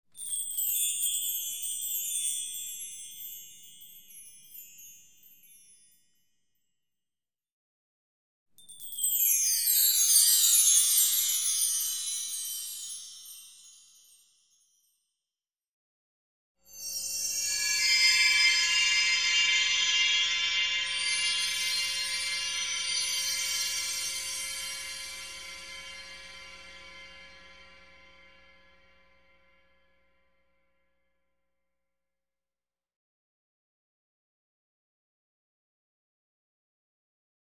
CAMPANILLAS CASCABELES
Tonos EFECTO DE SONIDO DE AMBIENTE de CAMPANILLAS CASCABELES
Campanillas_-_Cascabeles.mp3